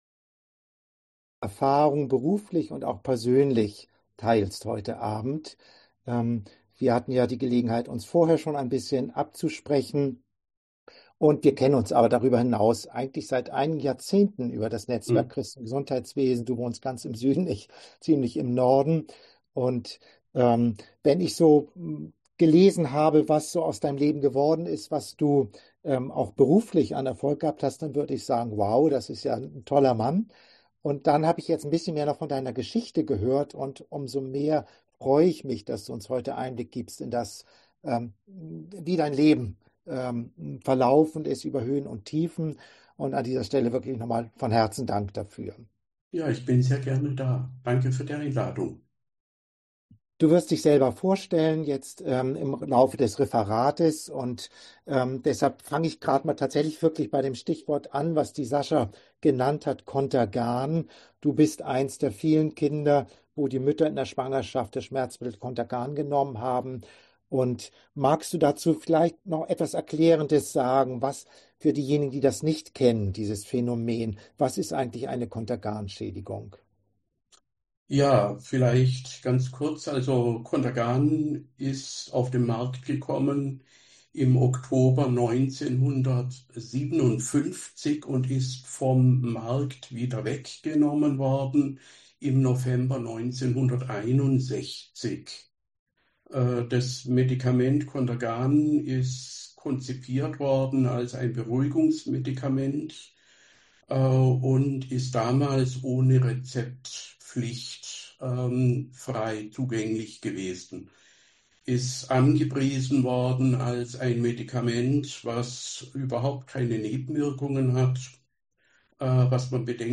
Digitales Kurzseminar